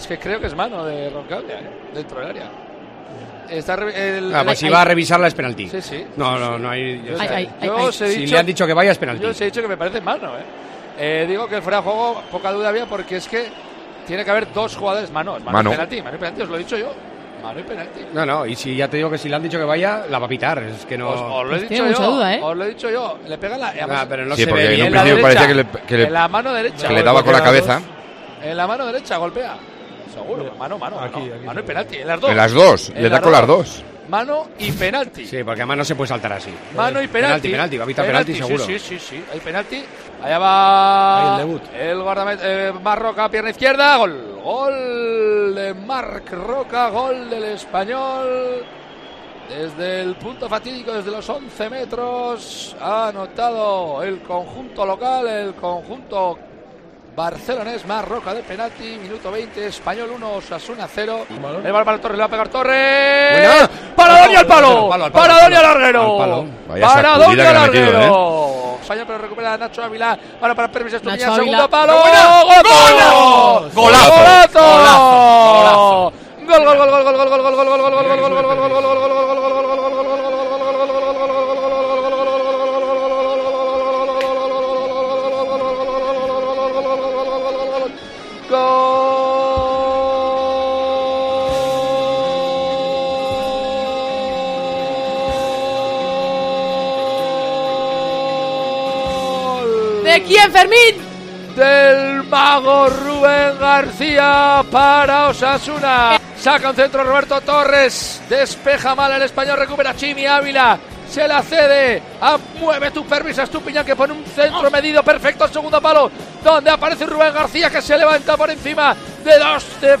Resumen del Espanyol-Osasuna en Tiempo de Juego Navarro.